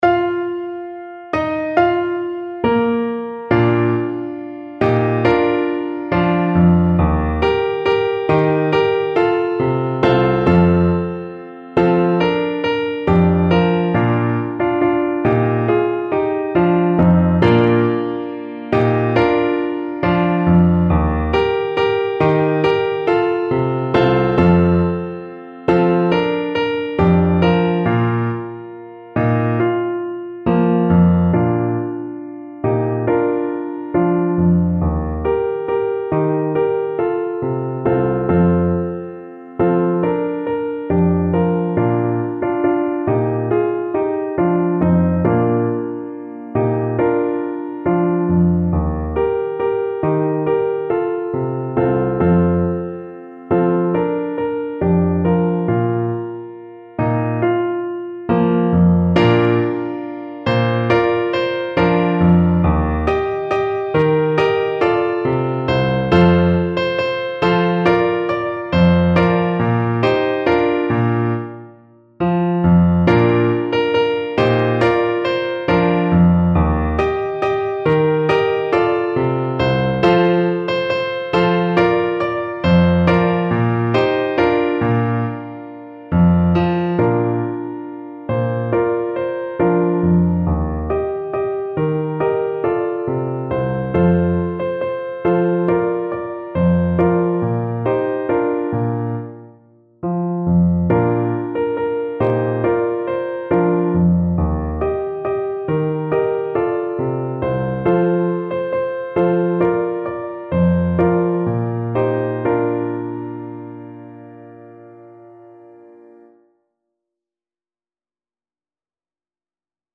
Clarinet version
Nobly =c.100
4/4 (View more 4/4 Music)
Traditional (View more Traditional Clarinet Music)
world (View more world Clarinet Music)